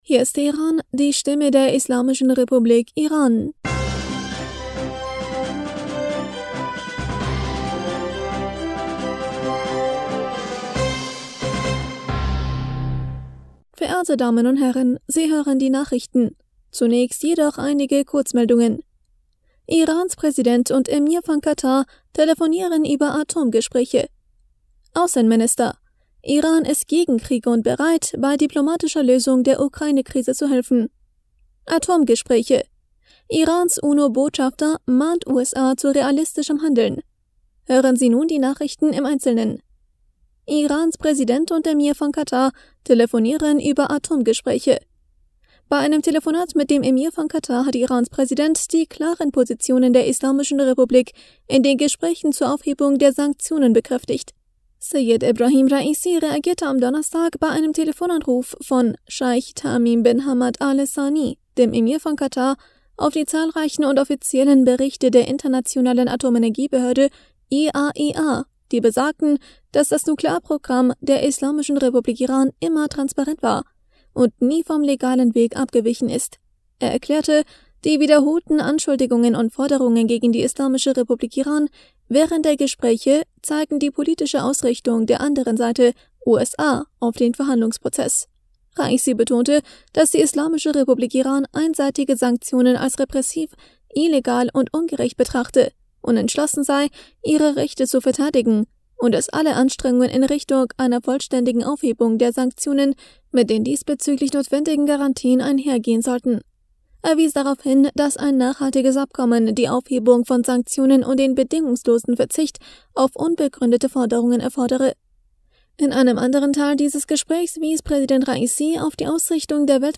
Nachrichten vom 1. Juli 2022